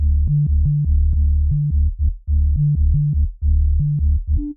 000-sine-bass.wav